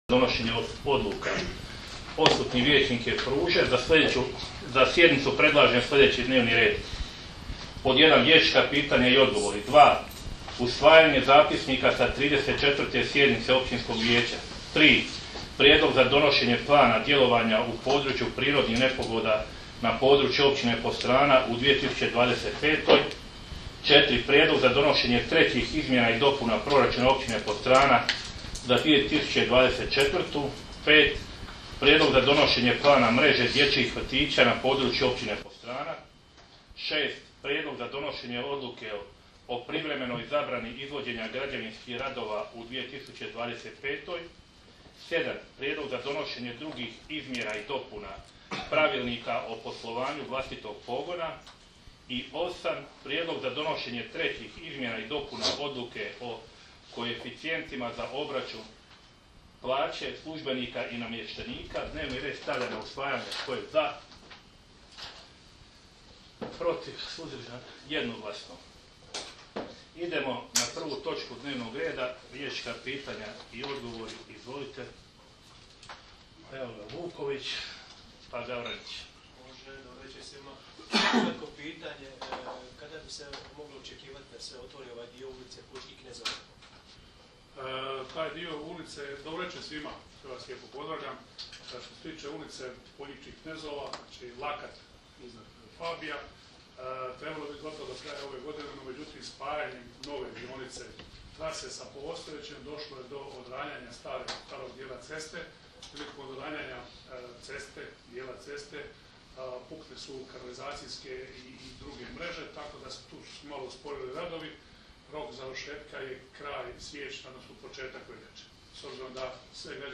Sjednica će se održati dana 19. prosinca (četvrtak) 2024. godine u 19,00 sati u Vijećnici Općine Podstrana.